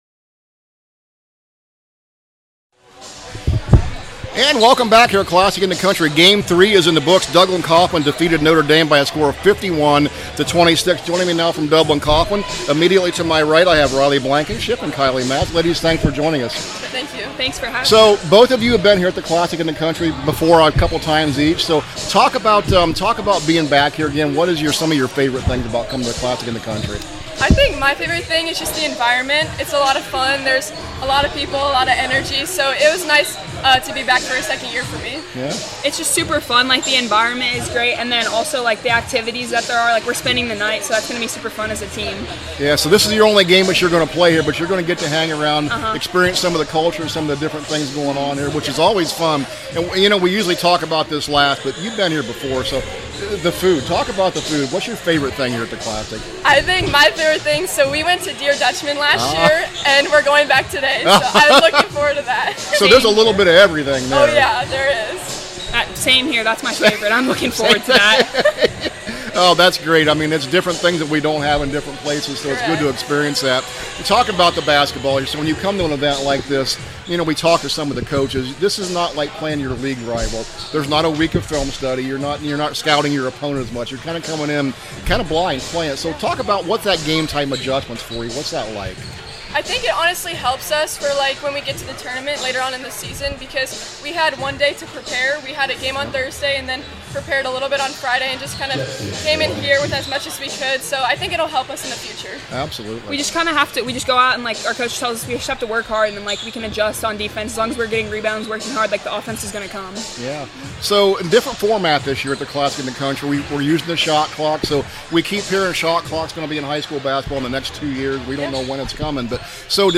2026 CLASSIC – DUBLIN COFFMAN PLAYER INTERVIEWS